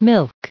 added pronounciation and merriam webster audio
507_milk.ogg